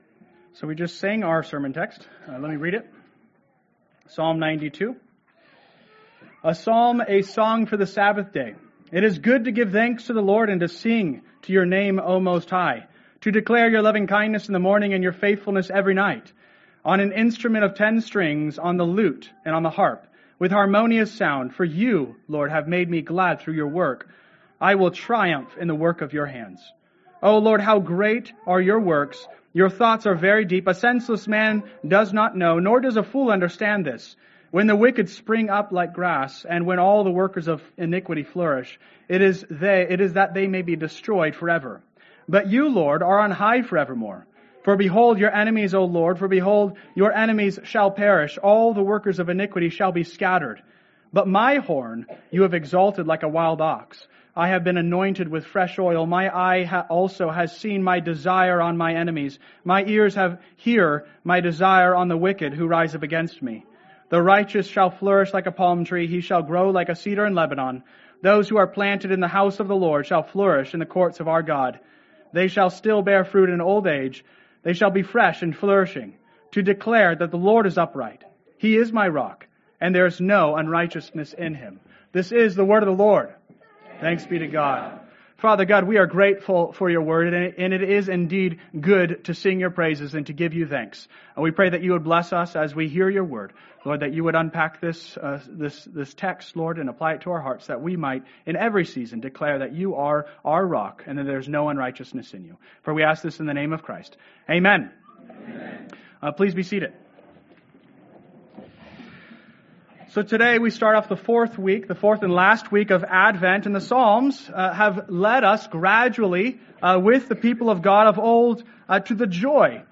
Sermon Outline: 12-22-24 Outline Jesus in the Psalms (Psalm 92, The Chief End of Man)